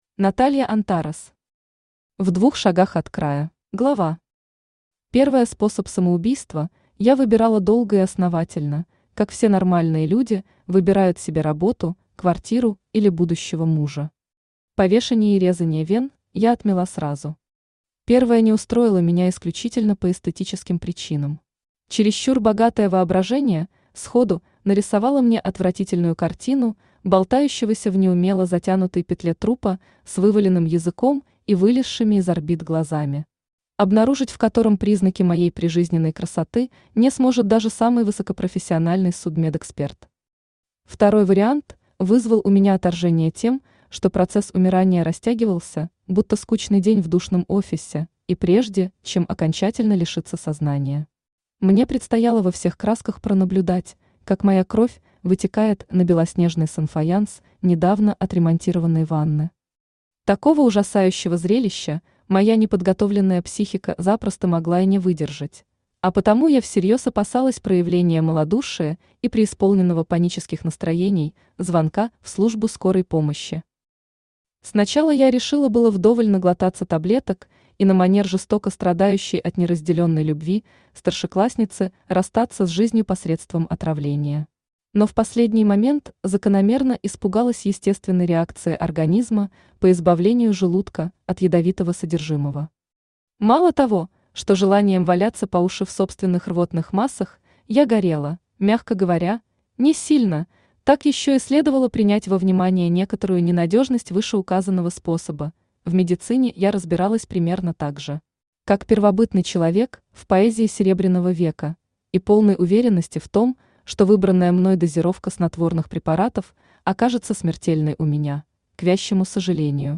Аудиокнига В двух шагах от края | Библиотека аудиокниг
Aудиокнига В двух шагах от края Автор Наталья Антарес Читает аудиокнигу Авточтец ЛитРес.